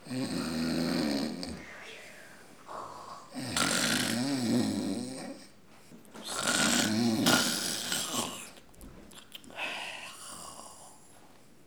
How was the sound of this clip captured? ajout des sons enregistrés à l'afk